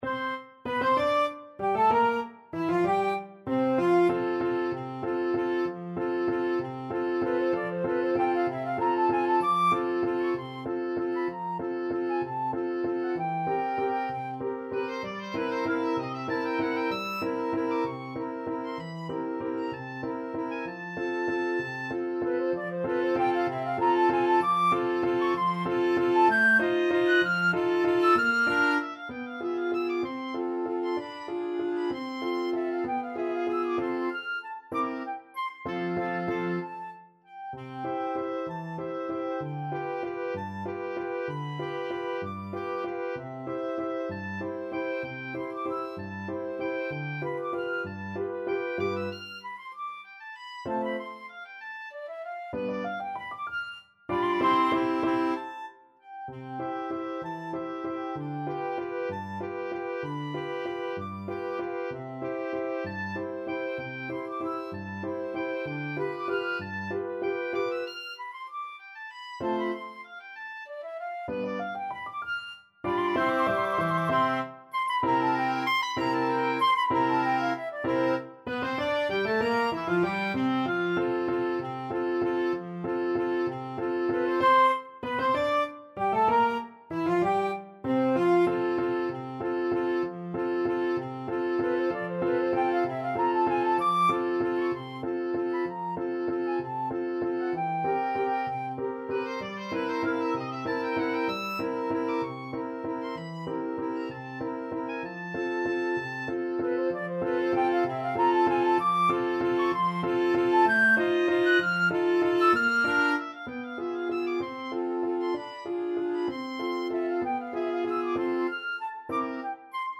FluteTrumpet
OboeViolin
Clarinet
ClarinetTrombone
3/4 (View more 3/4 Music)
Tempo di Waltz (.=c.64)